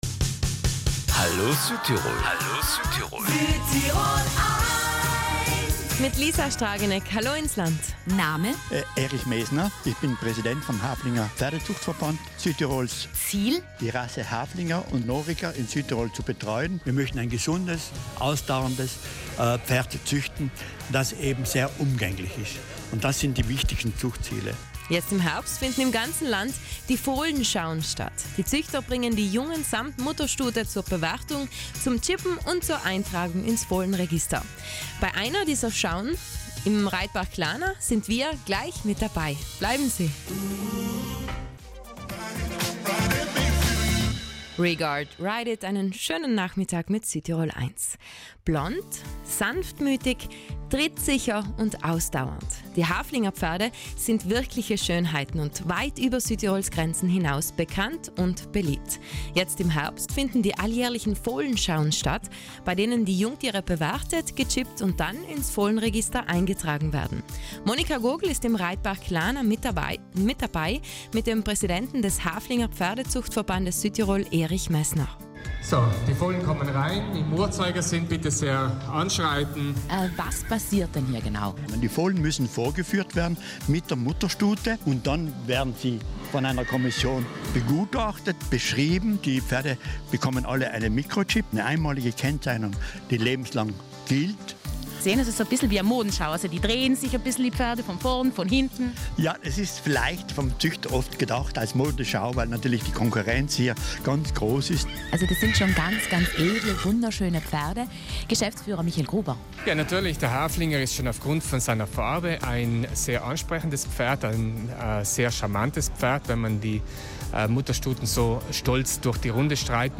Jetzt im Herbst finden landesweit die Fohlenschauen statt, bei denen die Jungtiere bewertet und gechippt werden. Heute Nachmittag sind wir bei so einer Fohlenschau mit dabei.